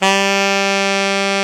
Index of /90_sSampleCDs/Roland L-CD702/VOL-2/SAX_Alto Short/SAX_A.ff 414 Sh
SAX A.FF G02.wav